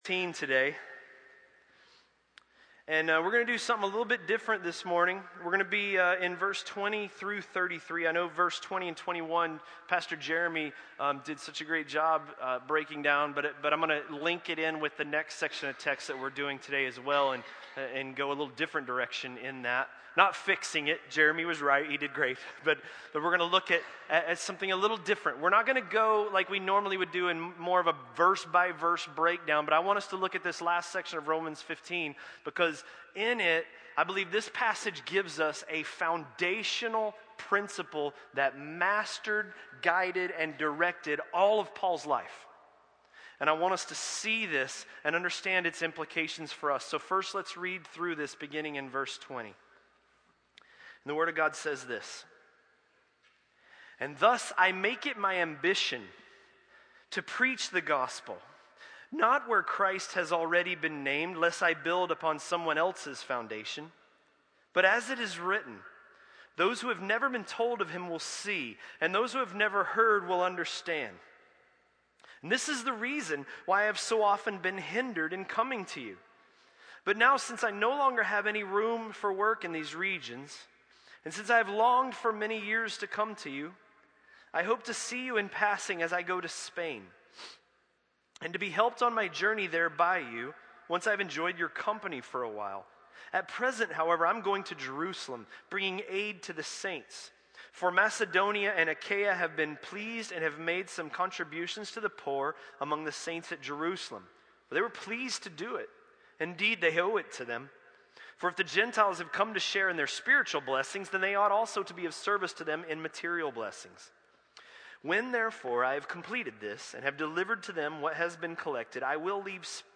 A message from the series "Romans."